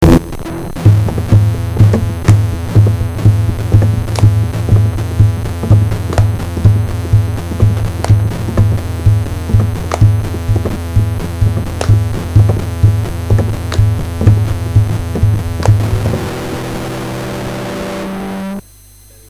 Beats
beat.mp3